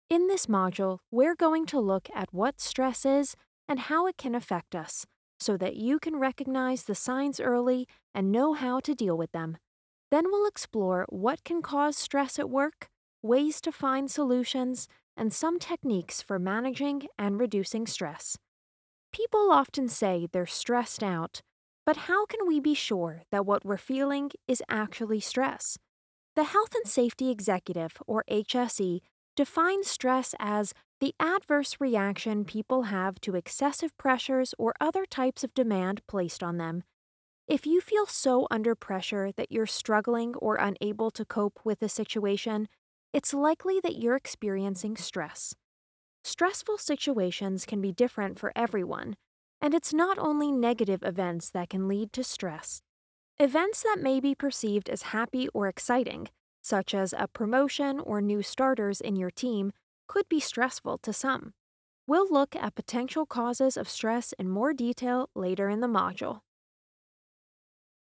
hell, fein, zart, sehr variabel
E-Learning
Narrative